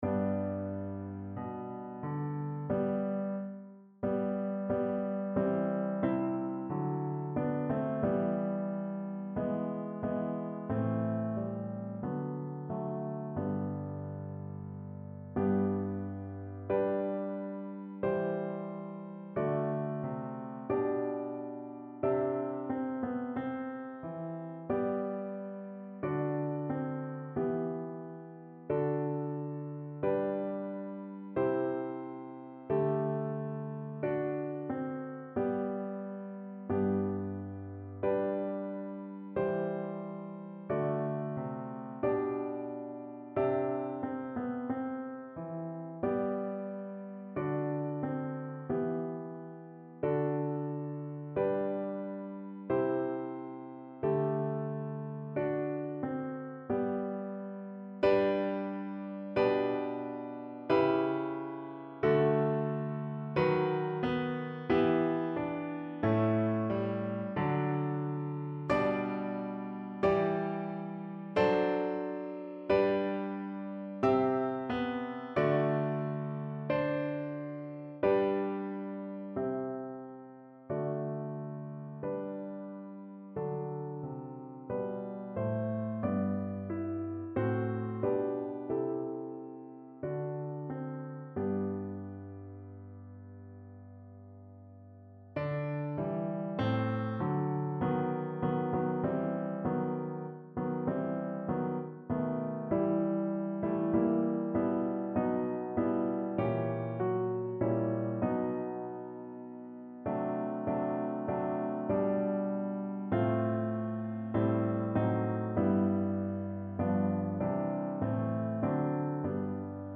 Notensatz (6 Stimmen gemischt)